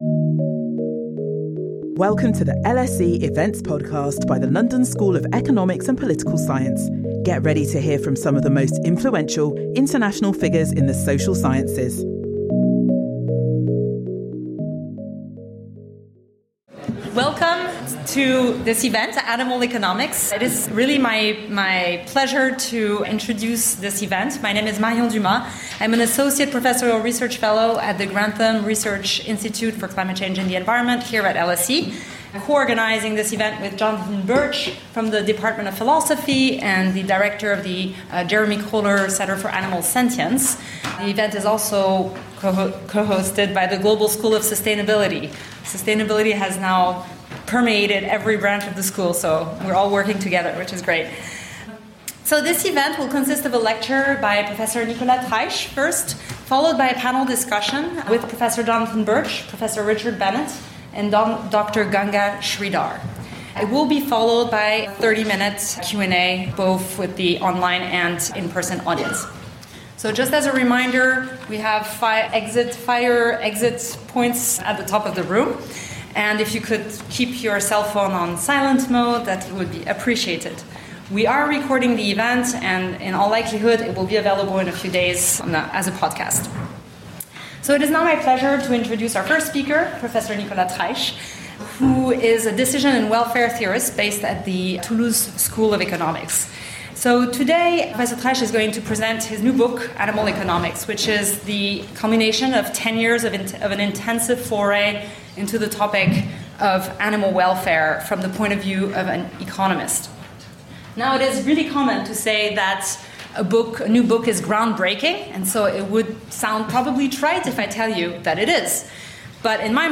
After the book presentation, a panel will debate and further explore the themes of the book.